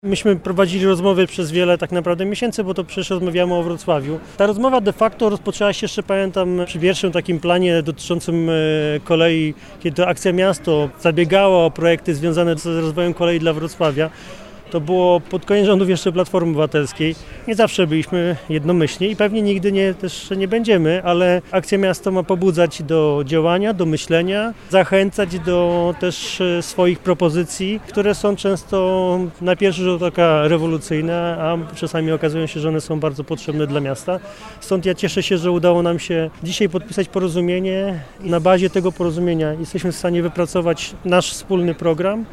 -Rozmowy trwały od długiego czasu. – zaznacza Michał Jaros, poseł na Sejm RP i przewodniczący Platformy Obywatelskiej na Dolnym Śląsku.